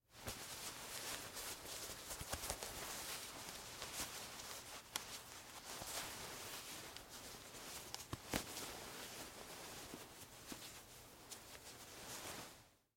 Звуки пальто
Застегиваем пуговицы на зимнем пальто